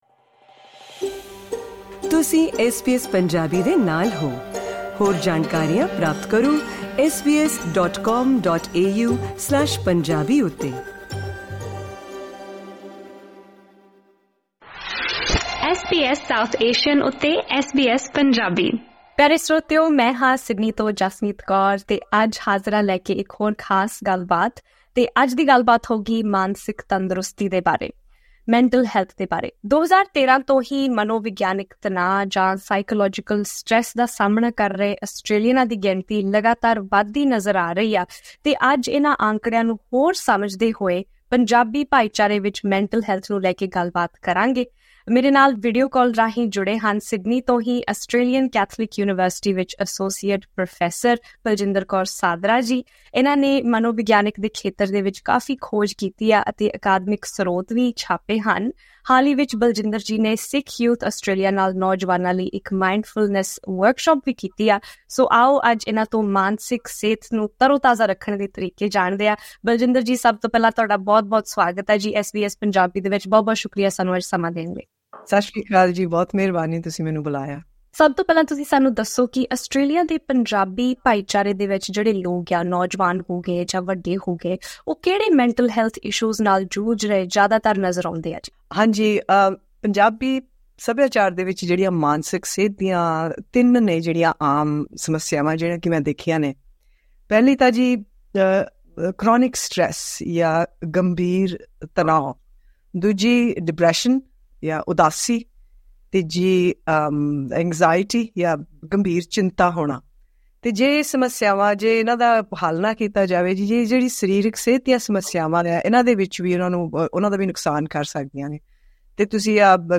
ਪੂਰੀ ਗੱਲਬਾਤ ਪੌਡਕਾਸਟ ਲਿੰਕ ਰਾਹੀਂ ਸੁਣੀ ਜਾ ਸਕਦੀ ਹੈ